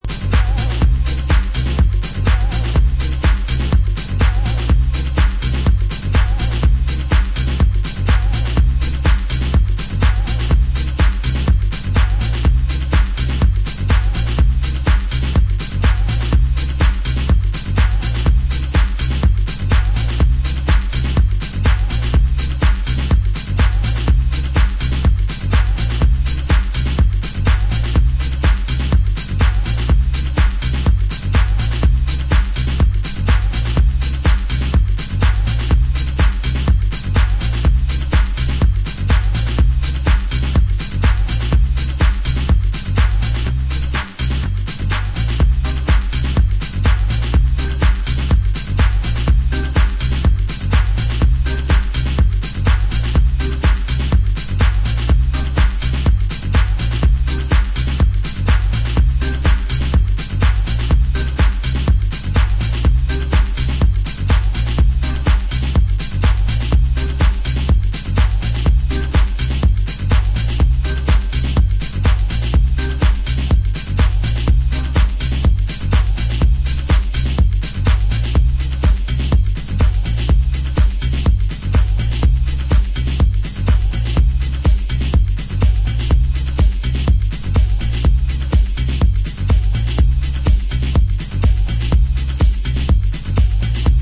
Effective vinyl only dj tools
updated 90's house style!